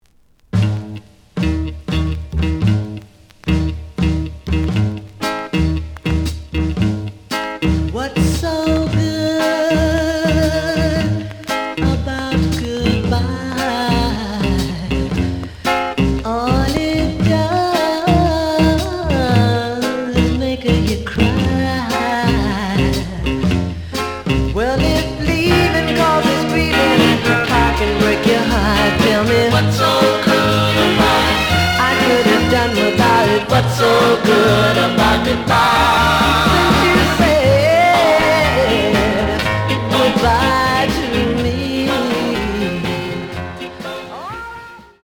The audio sample is recorded from the actual item.
●Genre: Soul, 60's Soul
Some click noise on both sides due to scratches.)